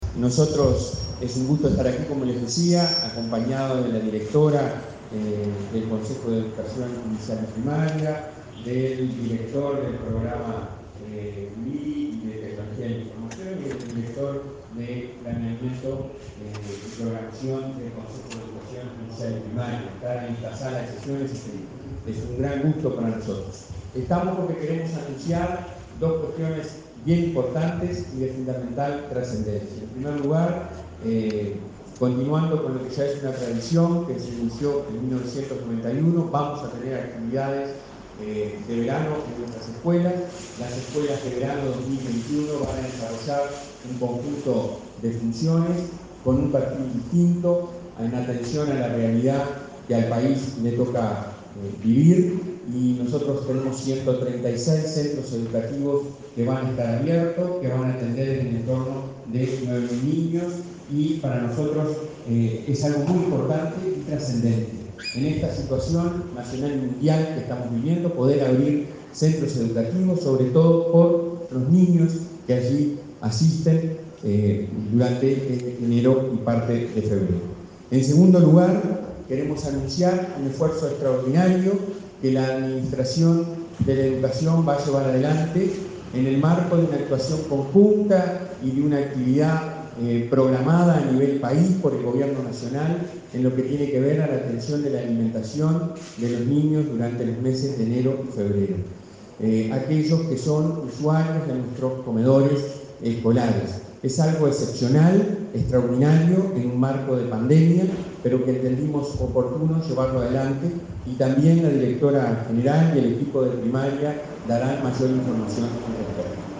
Conferencia de prensa del titular de la Administración Nacional de Educación Pública, Robert Silva